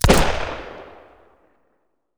Revolver_Shoot 01.wav